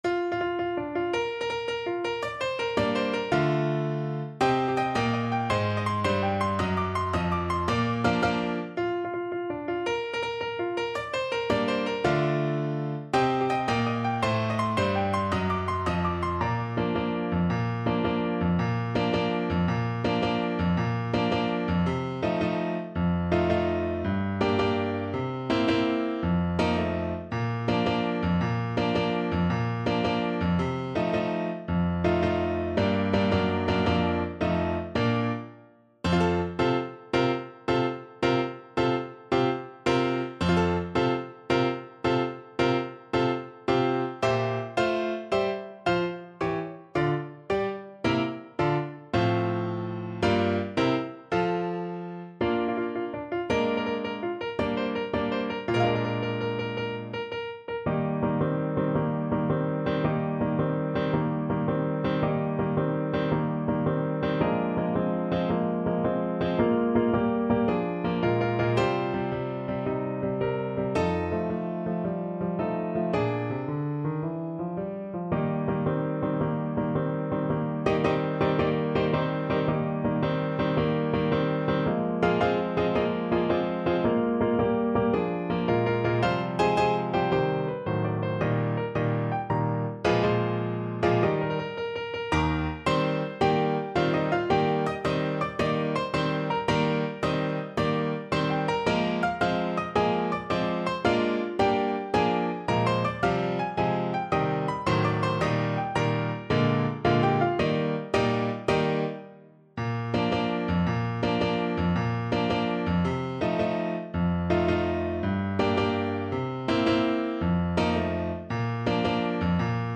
6/8 (View more 6/8 Music)
March .=c.110